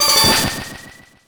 snd_icespell.ogg